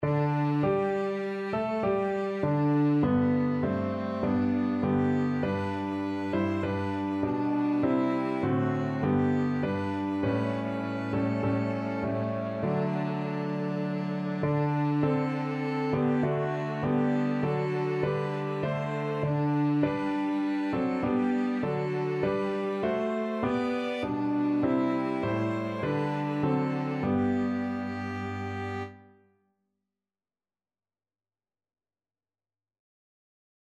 Violin
Clarinet
Cello
4/4 (View more 4/4 Music)